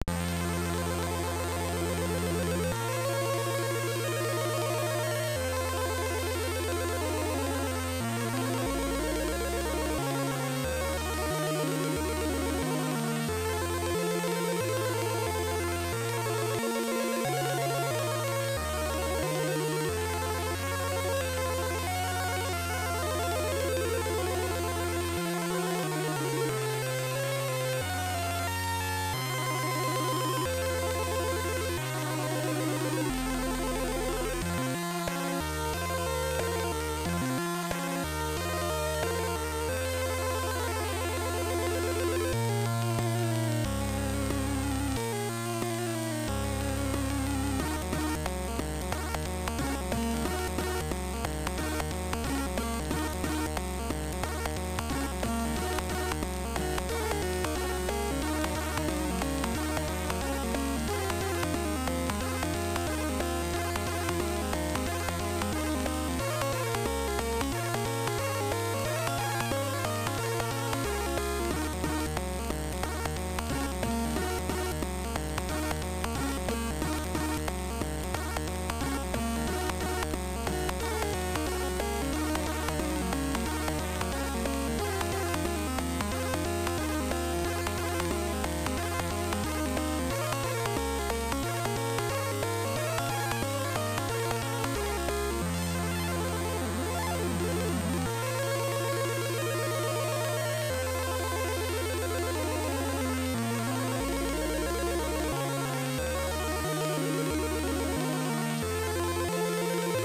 - eve2 gra pół tonu wyżej od eve1.